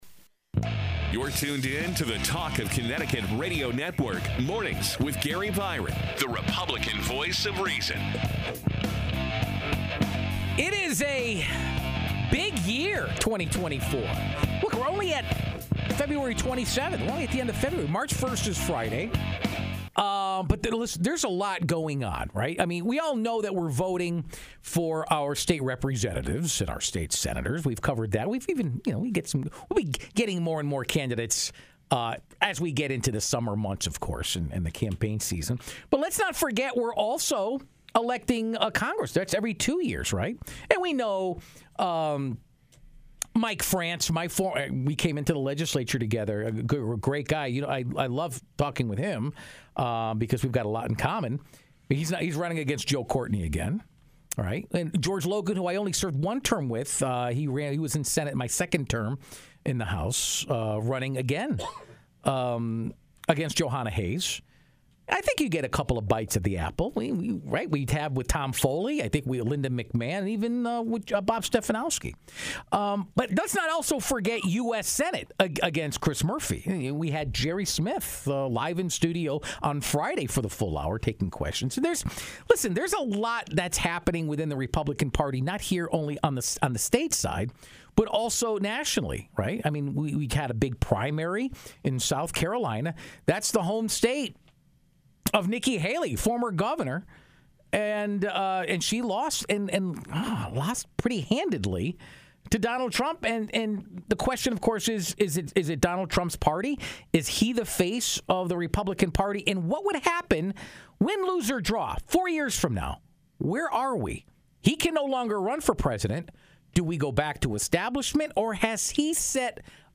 answer questions from callers